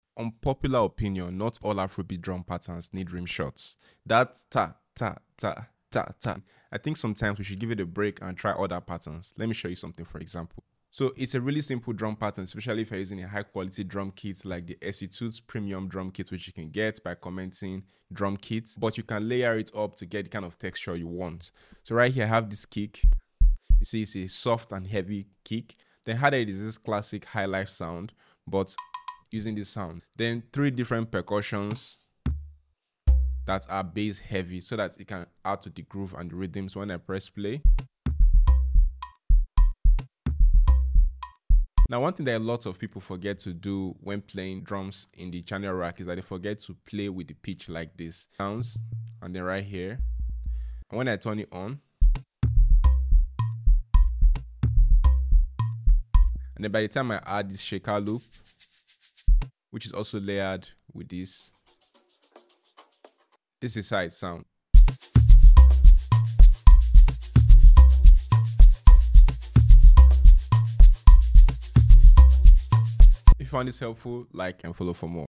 How to make Afrobeat Drum sound effects free download
How to make Afrobeat Drum pattern in FL Studio - Music production tip